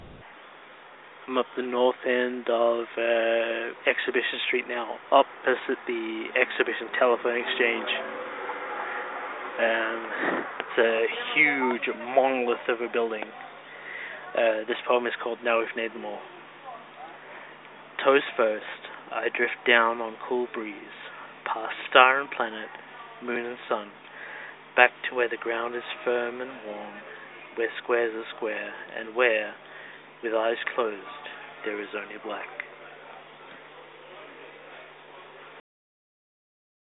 and now  I have; LOWWIRE is  a collection of poems  by me read
into payphones, I hope you enjoy.